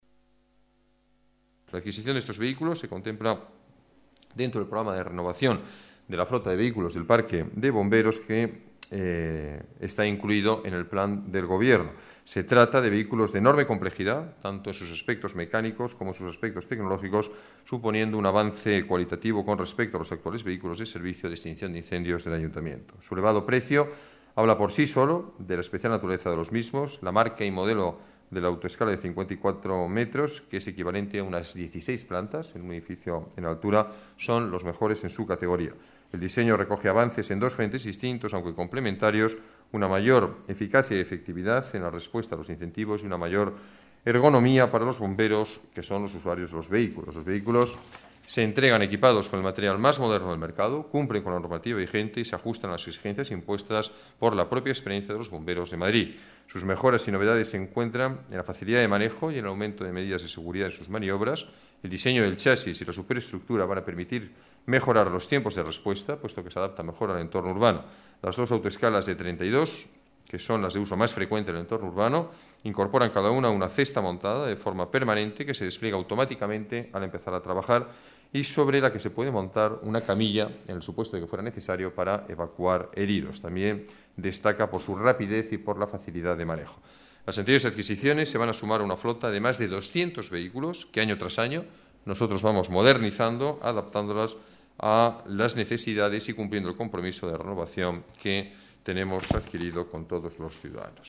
Nueva ventana:Declaraciones del alcalde, Alberto Ruiz-Gallardón, sobre la renovación de la flota de bomberos